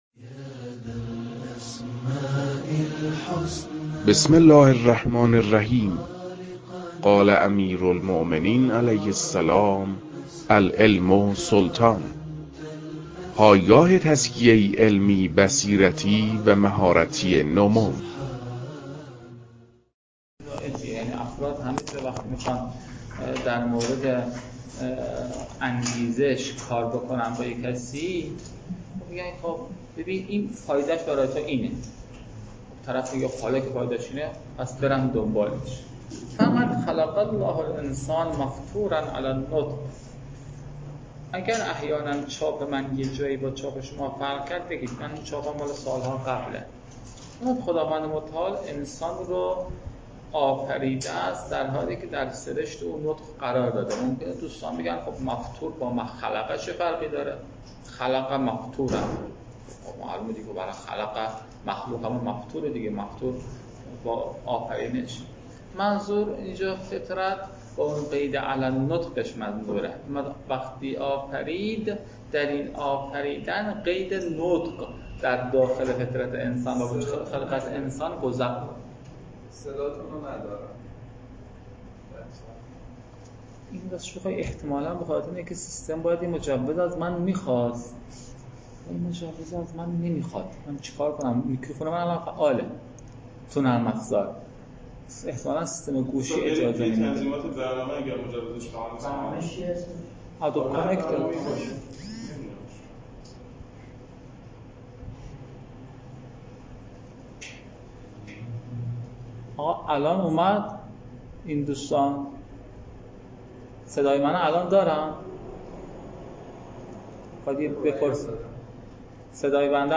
روخوانی «الحاجة الی المنطق»
در این بخش، کتاب «منطق مظفر» که اولین کتاب در مرحلۀ شناخت علم منطق است، به صورت ترتیب مباحث کتاب، تدریس می‌شود.